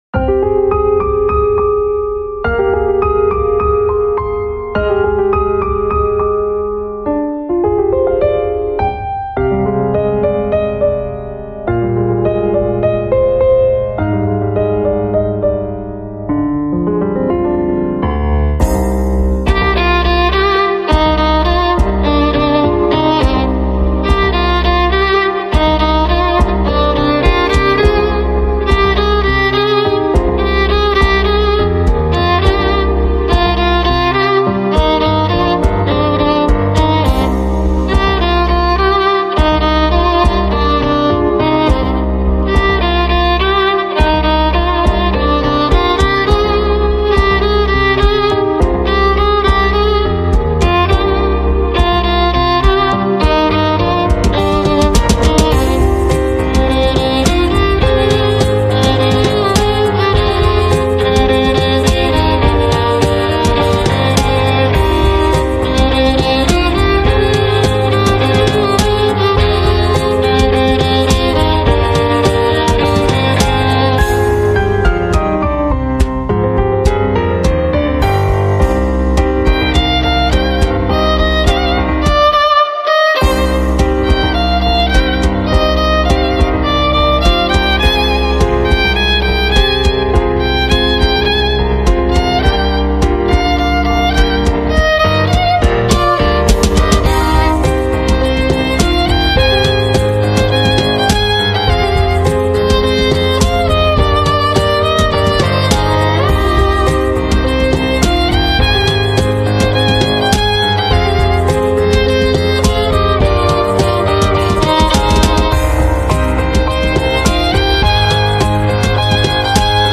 Помогите пожалуйста знающие с мастерингом, записал часть трека, капу обработал EQ (убрал ниже 80, местами поподнимали чтоб позвочне было) + С1 comp + psp vintage + waves trueverb небольшой.